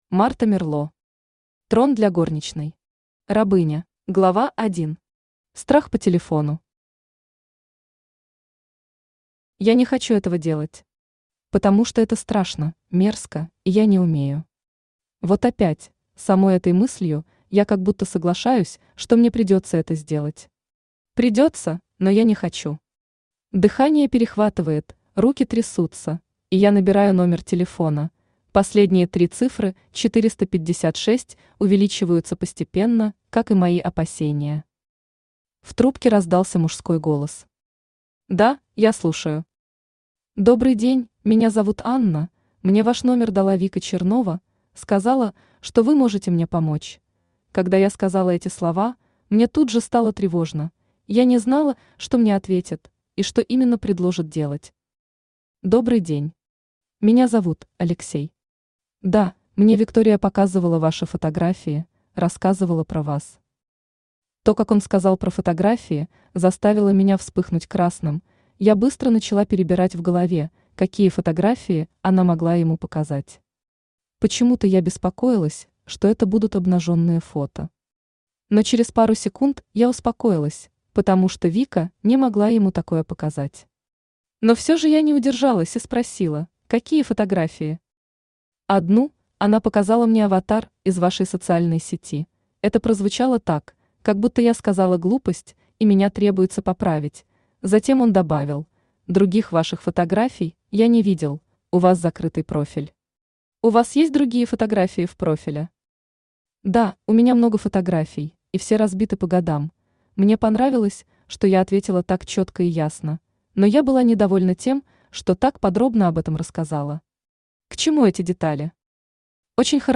Aудиокнига Трон для горничной. Рабыня Автор Марта Мерло Читает аудиокнигу Авточтец ЛитРес.